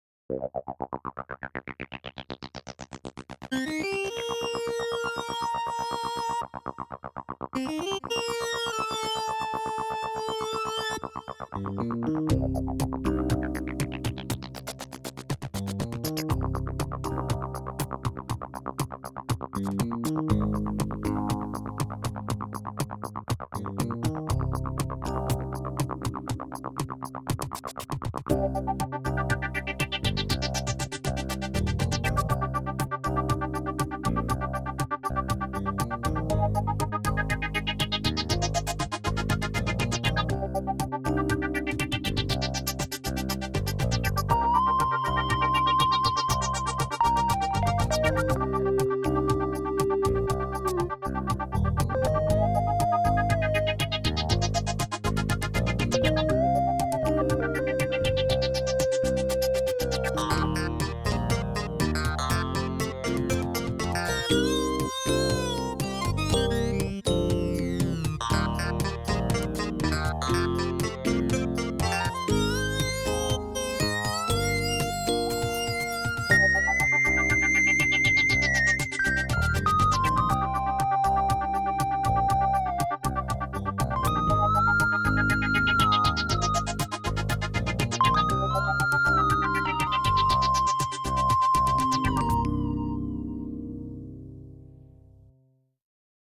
ManyOne comes with a wide range of sounds in four categories: electric pianos, ensembles, organs, and waveforms (which include classic analog waves) that are all selectable from the easy to use sound browser.
with a drum kit